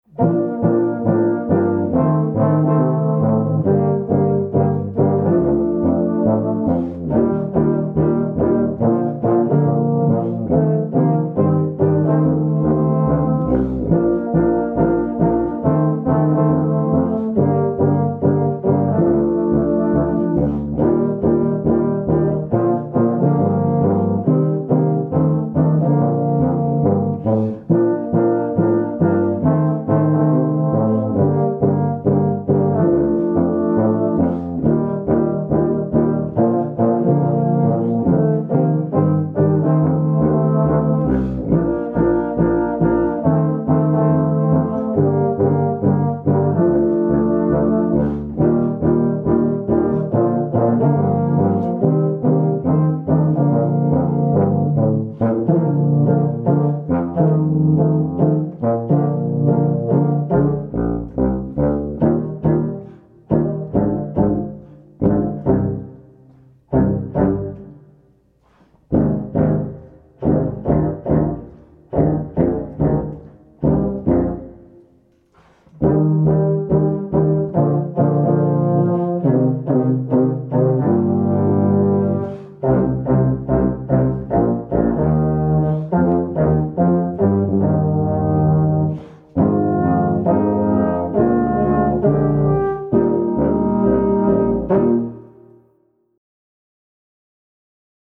The classic Christmas song